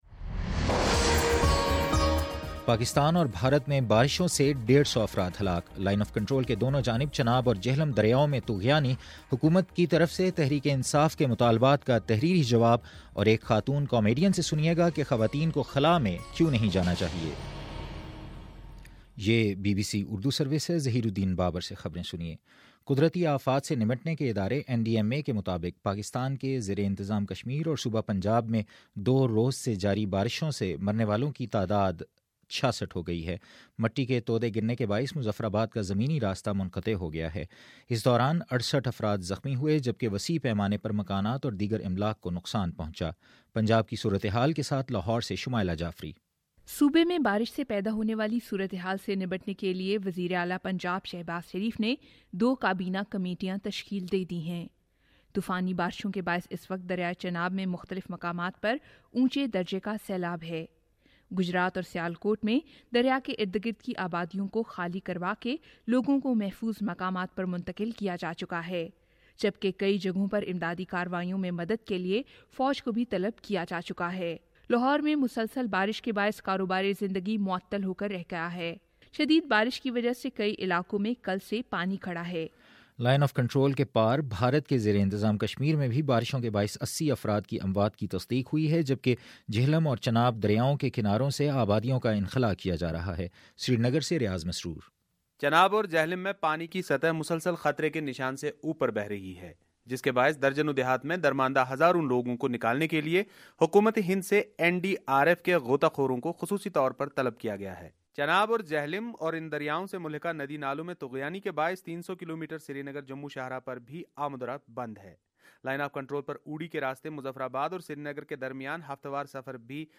پانچ ستمبر : شام سات بجے کا نیوز بُلیٹن